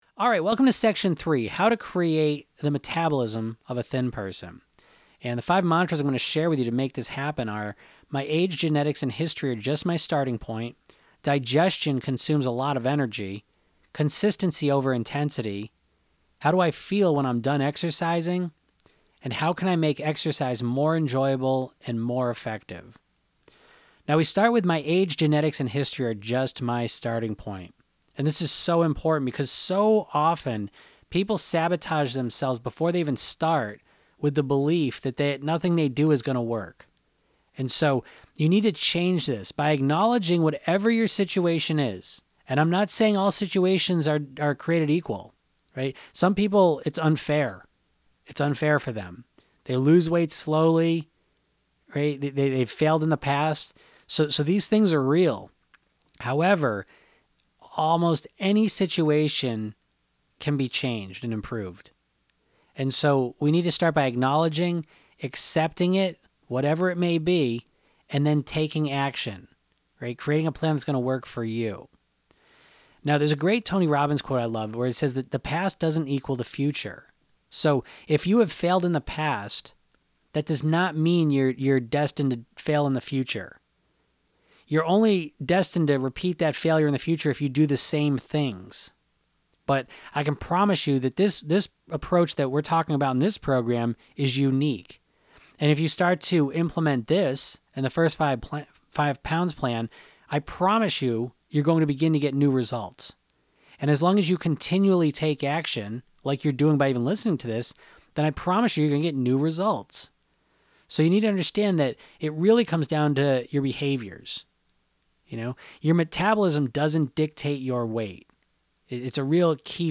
Self Hypnosis Session:Create The Metabolism of A Thin Person